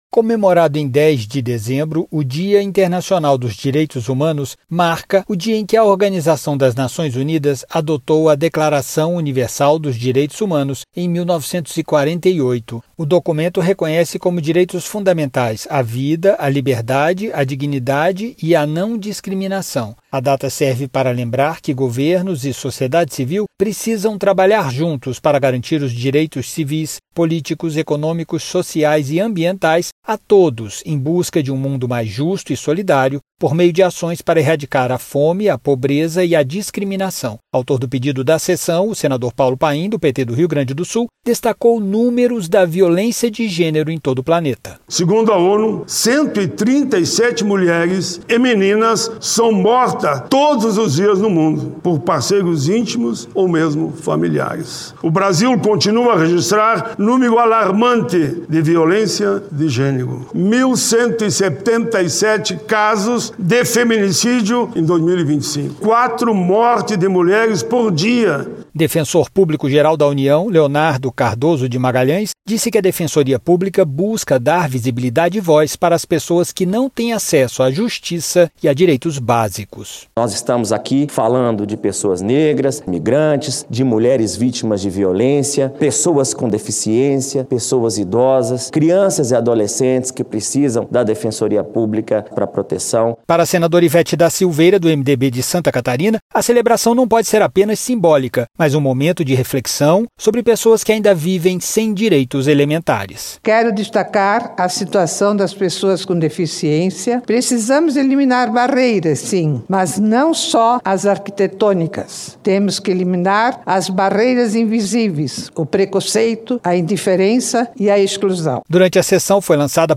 O Senado promoveu nesta segunda-feira (8) uma sessão especial para destacar o Dia Internacional dos Direitos Humanos, que será celebrado no dia 10 de dezembro. Durante a cerimônia, o senador Paulo Paim (PT-RS) alertou para a violência contra as mulheres, citando várias estatísticas, inclusive de feminicídios.